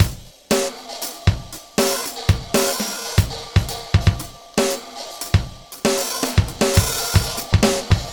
Gliss 2fer 4 Drumz.wav